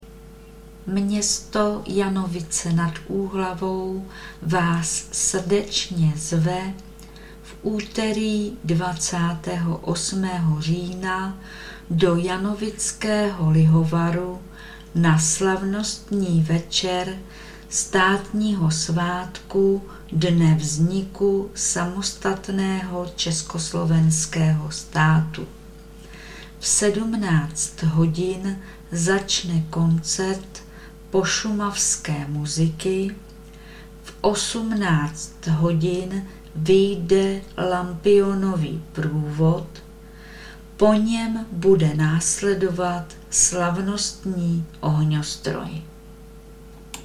ohnostroj.mp3